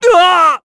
Esker-Vox_Damage_kr_03.wav